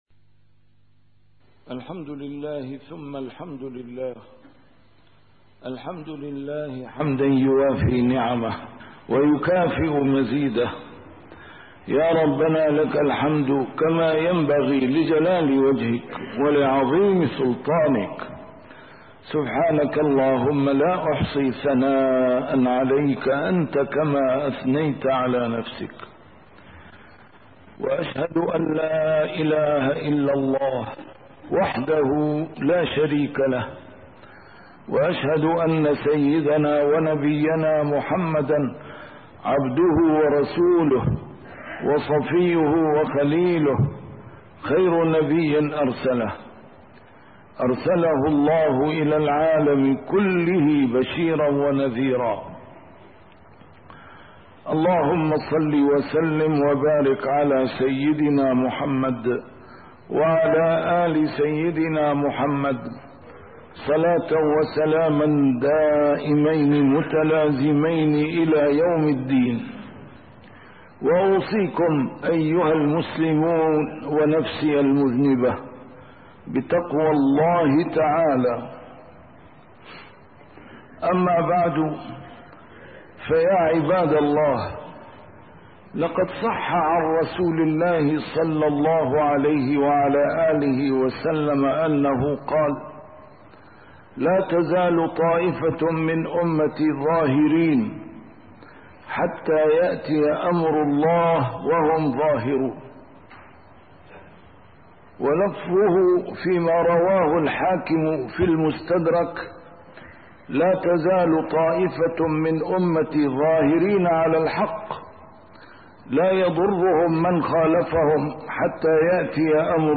A MARTYR SCHOLAR: IMAM MUHAMMAD SAEED RAMADAN AL-BOUTI - الخطب - لا تزال طائفةٌ من أمتي ظاهرين